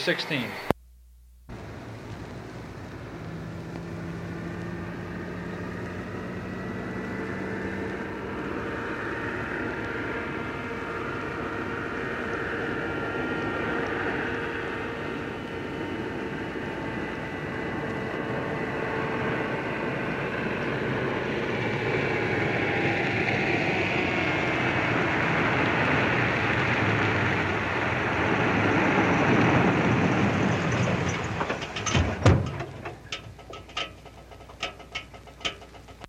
老式坦克和推土机 " G1519推土机工作原理
描述：溅射，非常沉重的吱吱作响的车辆。开车经过并停下来。发动机静静地怠速运转，再次启动，备用和机动几次。工作声音。 这些是20世纪30年代和20世纪30年代原始硝酸盐光学好莱坞声音效果的高质量副本。 40年代，在20世纪70年代早期转移到全轨磁带。我已将它们数字化以便保存，但它们尚未恢复并且有一些噪音。
标签： 运输 光学 经典 推土机
声道立体声